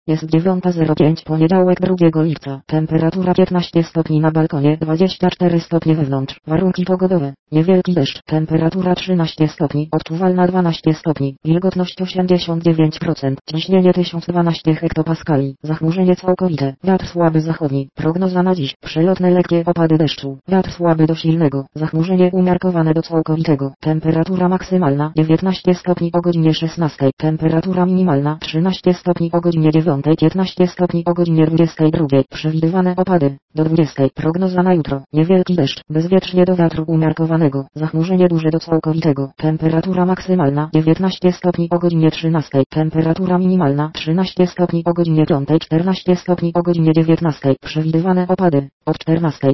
Przykładowy odczyt (2 lipca 2024, Bielsko-Biała):
Wszystkie wersje korzystają z syntezatora mowy i identycznego wzmacniacza.